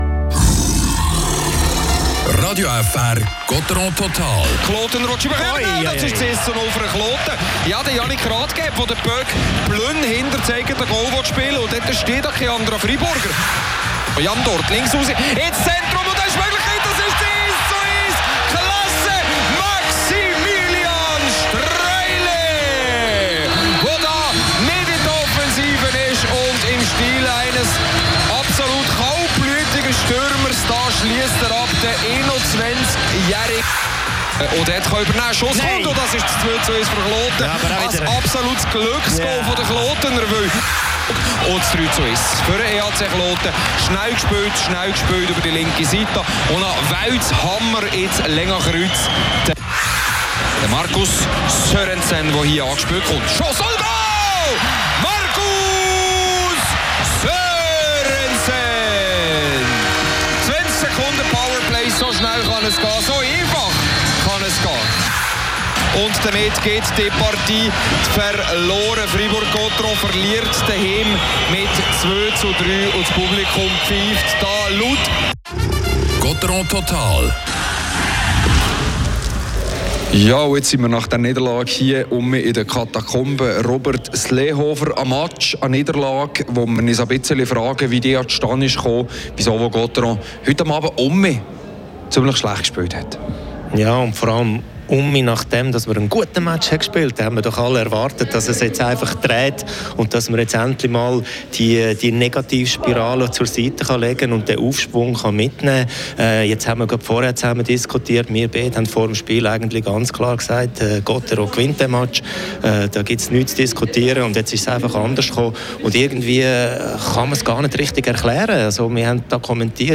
das Interview mit Yannick Rathgeb.